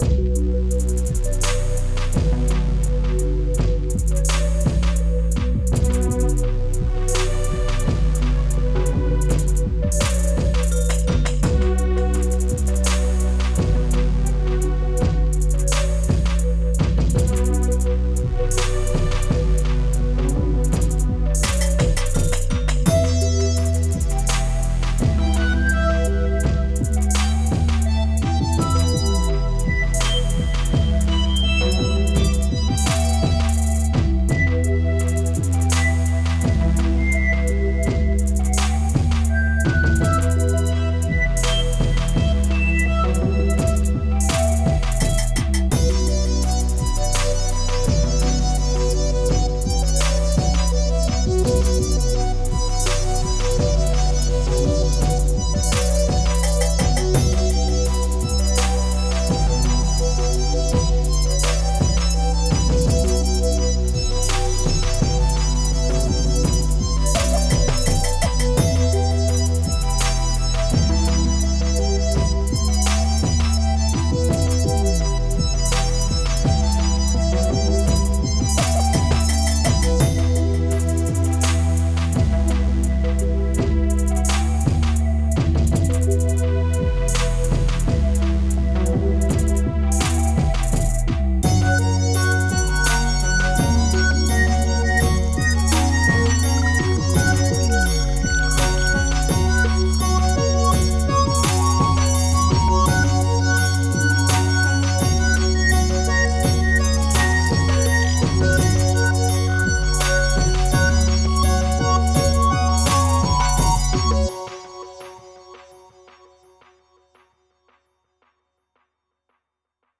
Musique avec synthetiseurs. Tempo 84 bpm.